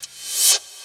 Key-rythm_cymbal_161.4.1.wav